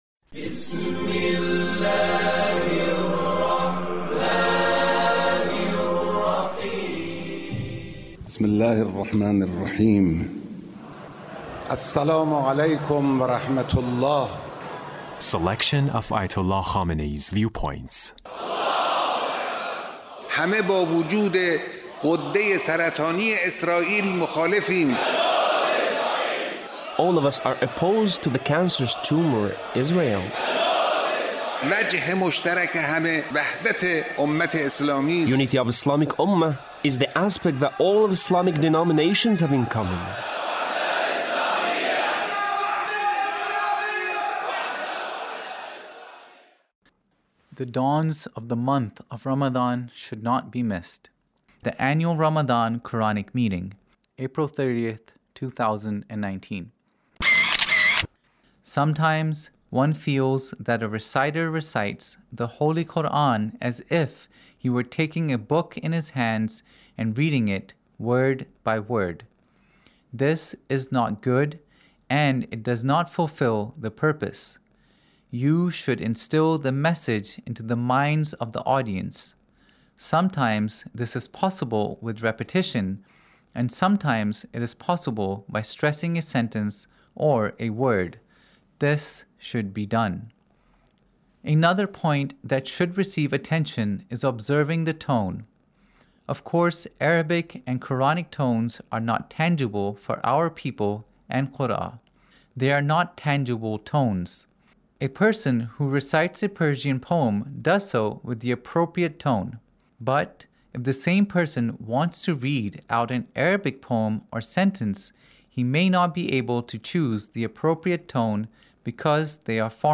Leader's Speech On The Month of Ramadhan